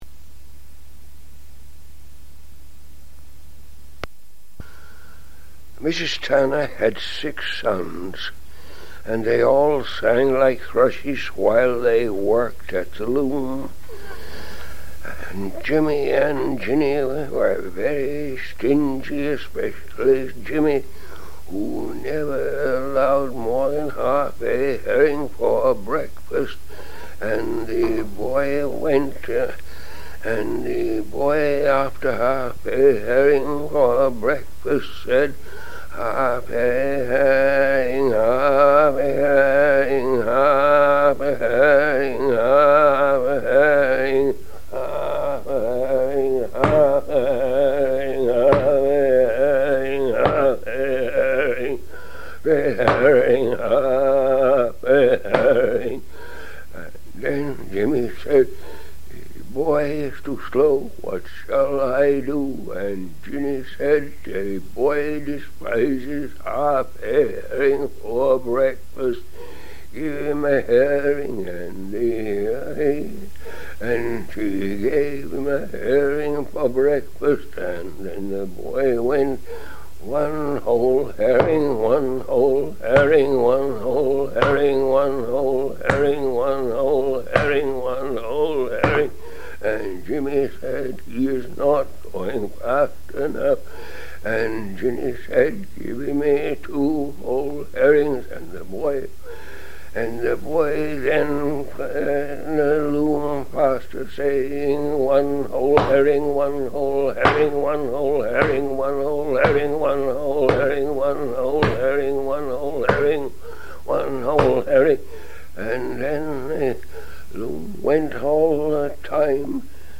Weaving song from the Gower Peninsula